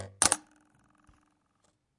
刀片振动和故障 " Knive7
描述：记录的刀片声音。
Tag: 刀片声 单击 打击乐器 录音 毛刺 叶片 振动 现场录音 拍摄 声音